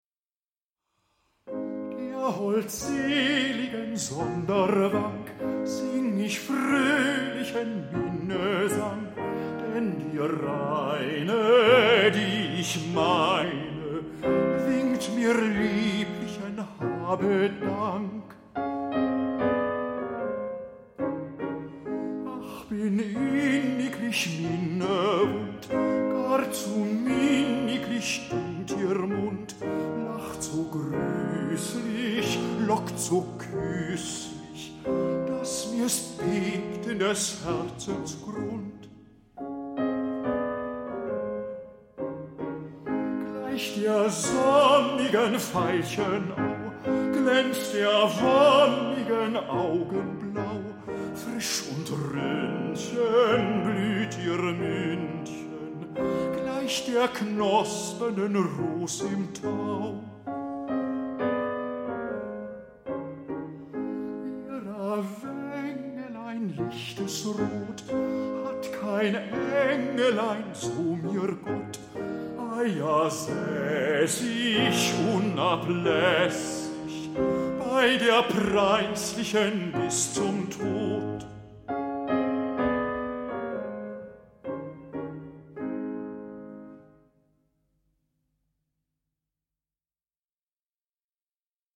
zurückhaltend, innig, fast scheu.